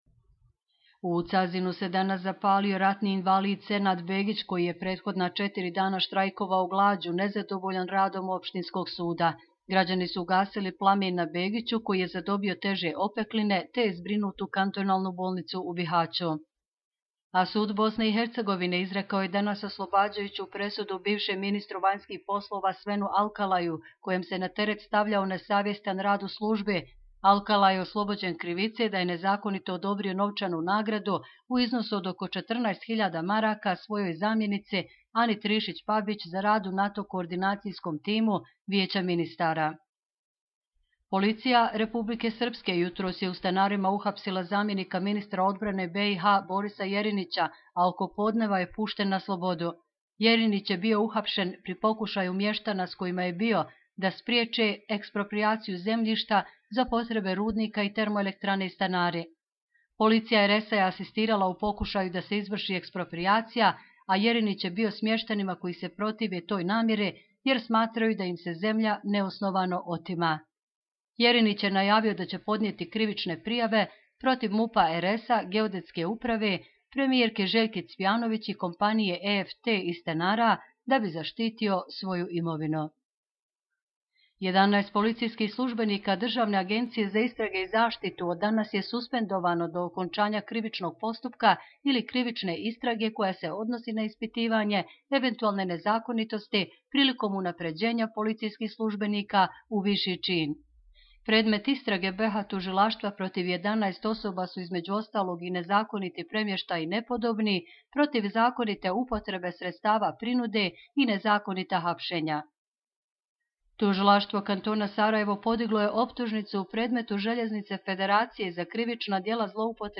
Audio izvještaji